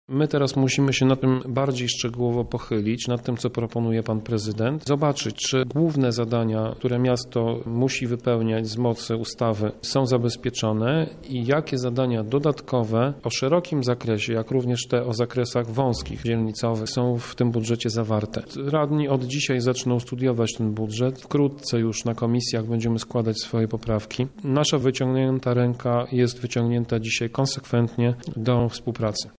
Projekt trafi teraz pod obrady poszczególnych komisji. Jak zaznaczył przewodniczący opozycyjnego klubu Prawa i Sprawiedliwości Tomasz Pitucha jest jeszcze zbyt wcześnie by ocenić przyszłoroczny budżet.